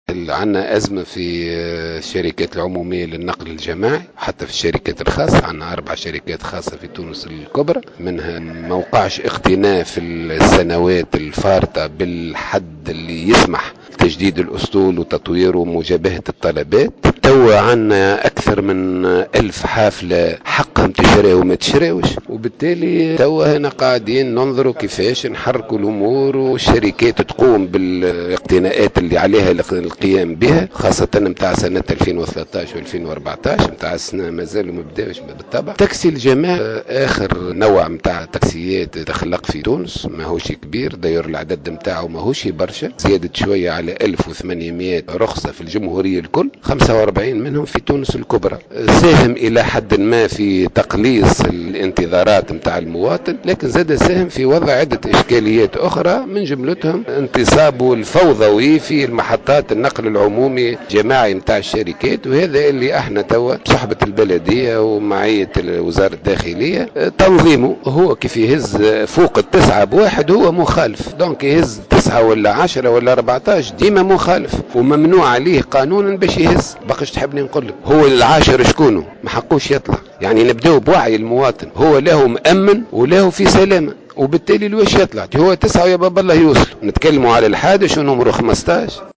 أكد فرج علي مدير عام النقل البريّ على هامش ندوة صحفية انعقدت اليوم بالعاصمة بإشراف وزير النقل أن هناك أزمة في الشركات العمومية للنقل الجماعي مؤكدا أن هذه الأزمة تعاني منها أيضا الشركات الخاصة حيث لم تقم الشركة باقتناءات جديدة في 4 شركات خاصة بما يسمح بتجديد الأسطول وتطويره لمجابهة الطلبات وفق قوله.